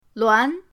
luan2.mp3